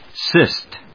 /síst(米国英語)/